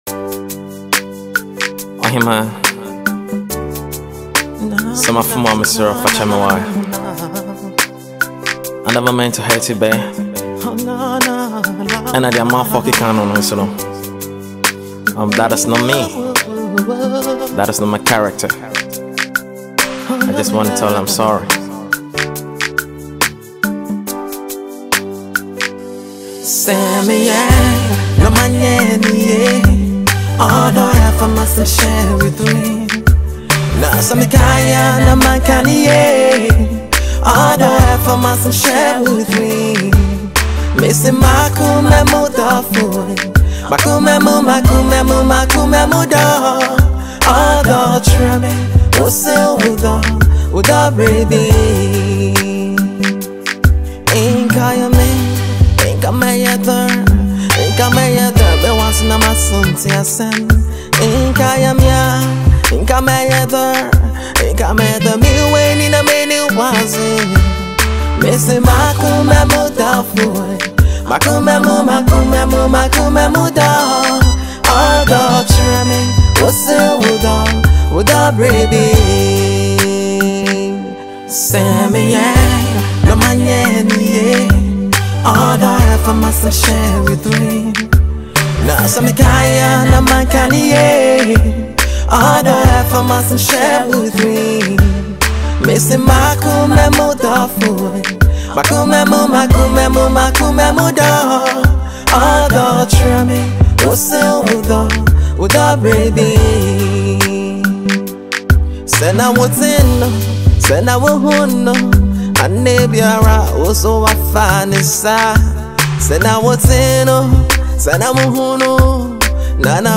Ghanaian USA based Afro highlife singer